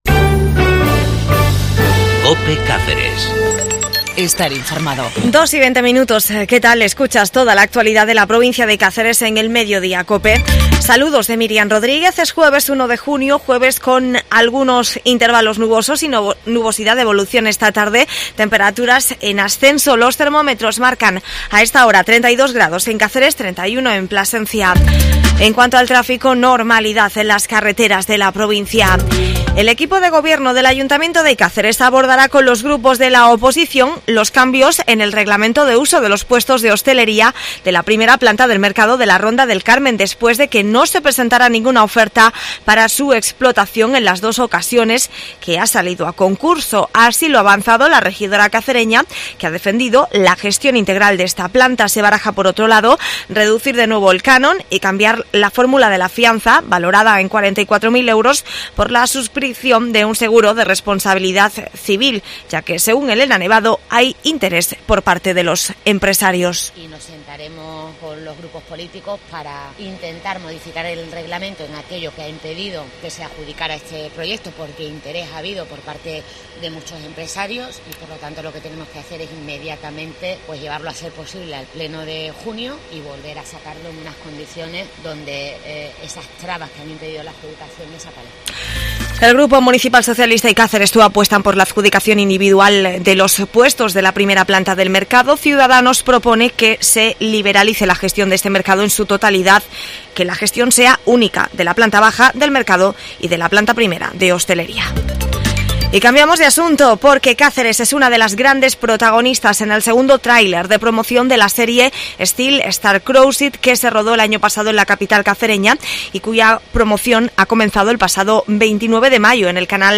01 JUNIO INFO LOCAL
AUDIO: informacion local del 1 de junio, caceres, cadena cope